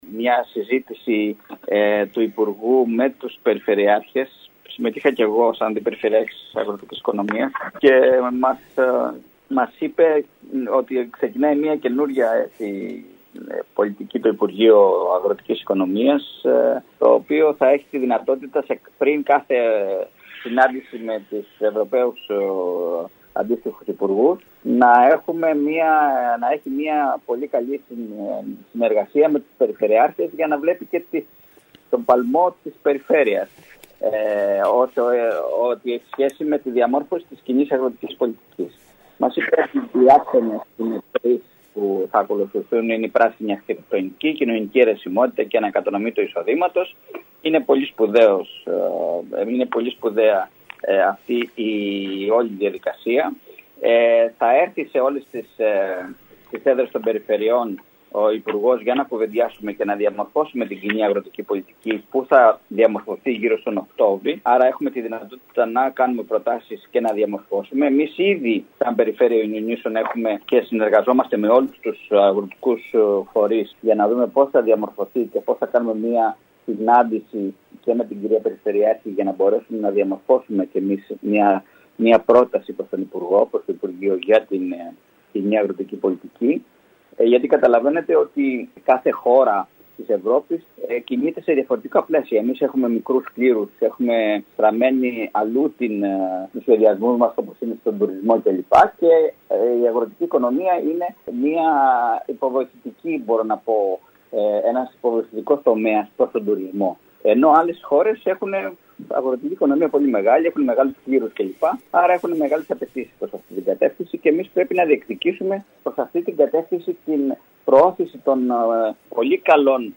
Ακούμε τον αντιπεριφερειάρχη Σωτήρη Κουρή.